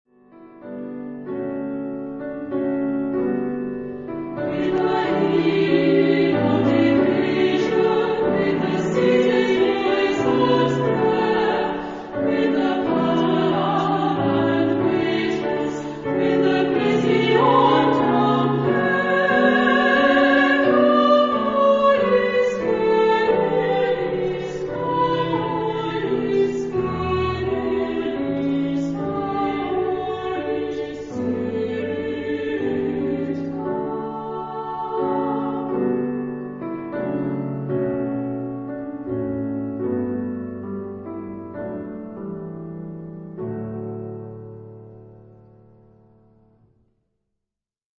Genre-Style-Forme : Sacré ; Motet
Caractère de la pièce : doux
Type de choeur : SA  (2 voix égales )
Instrumentation : Piano  (1 partie(s) instrumentale(s))
Tonalité : ré majeur